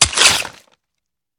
swipe3.ogg